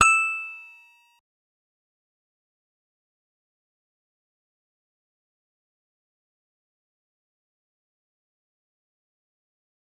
G_Musicbox-E7-mf.wav